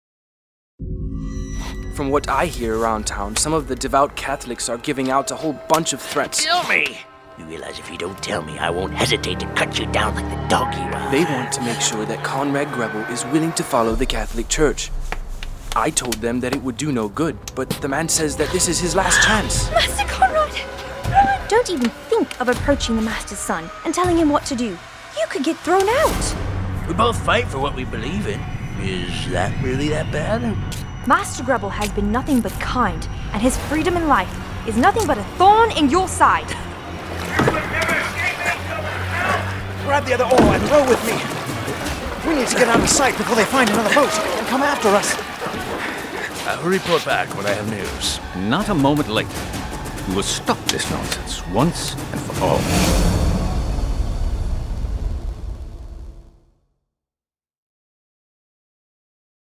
Etched-in-the-Flame-Audio-Trailer.mp3